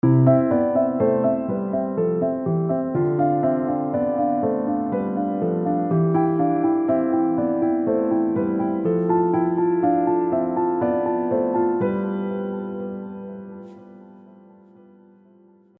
The two descending patterns played at a third, simultaneously moving up the scale, just sound freaking marvellous.